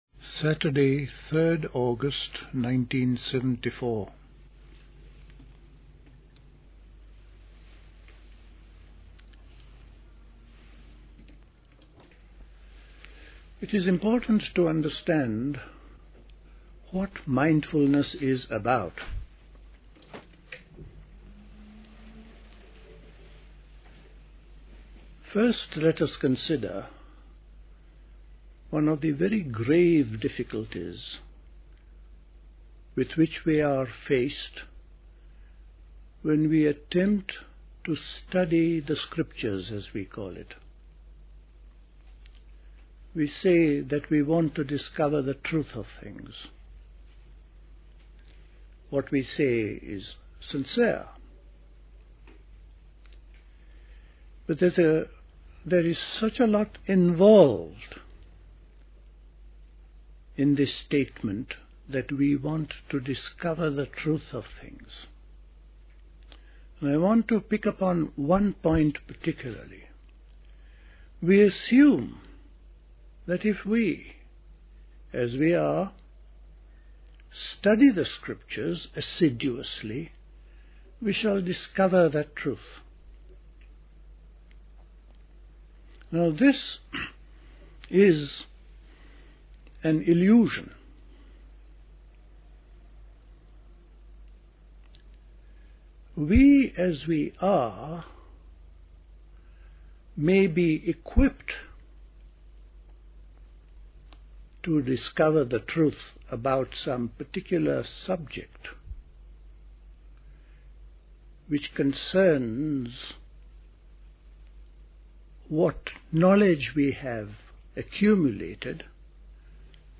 A talk
at Dilkusha, Forest Hill, London on 3rd August 1974